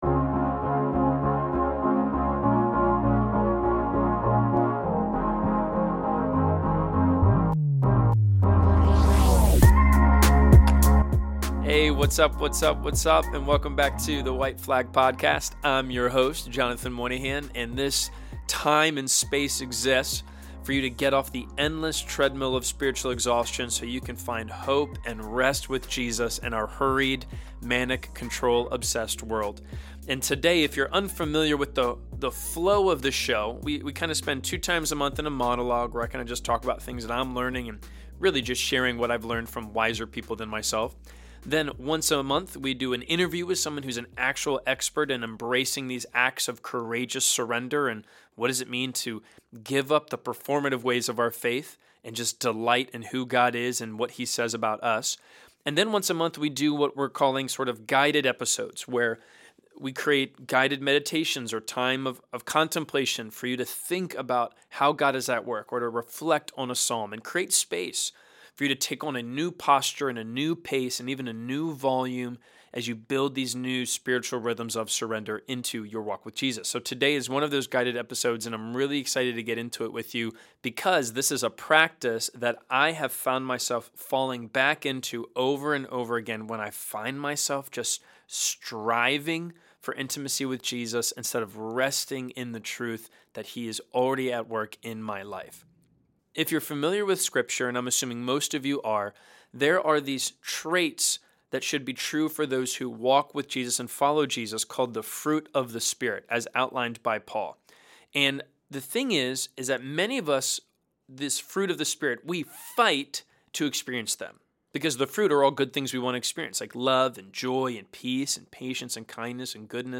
12. Guided Reflection: Breathing In the Fruit of the Spirit